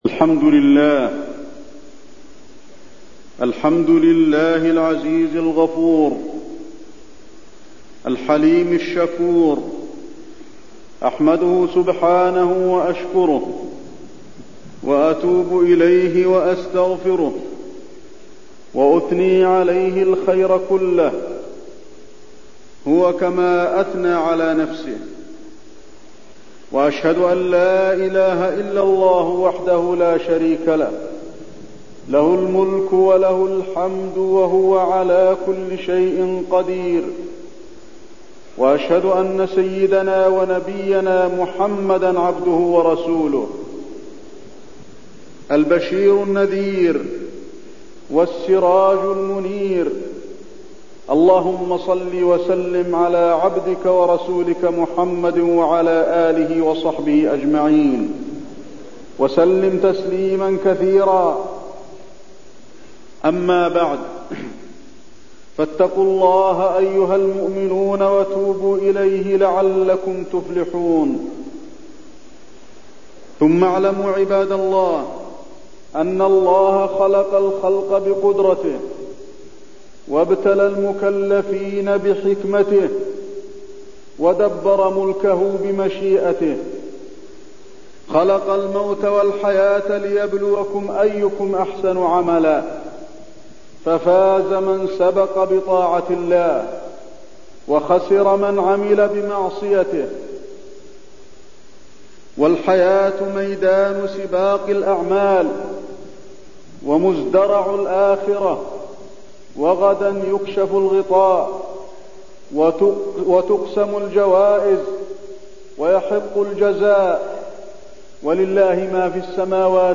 تاريخ النشر ٩ شعبان ١٤٠٦ هـ المكان: المسجد النبوي الشيخ: فضيلة الشيخ د. علي بن عبدالرحمن الحذيفي فضيلة الشيخ د. علي بن عبدالرحمن الحذيفي المبادرة للتوبة